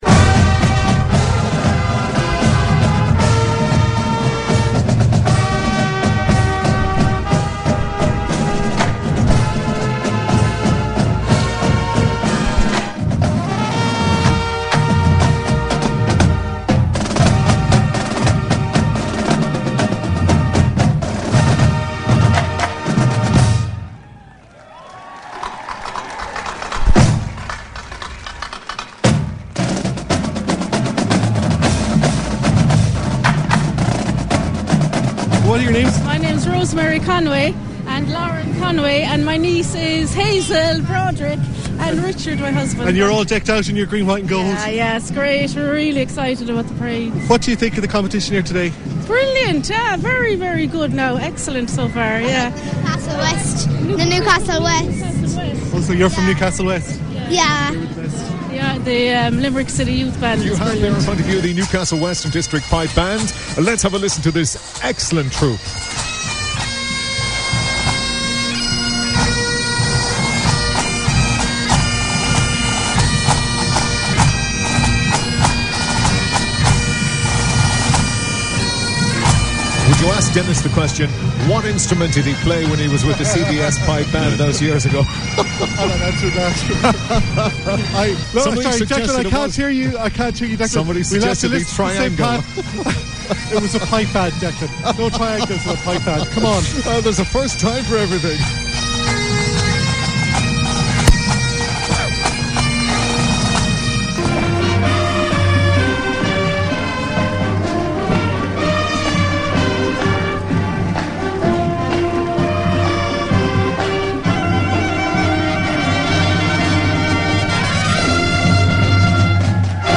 Highlights from the International Band Parade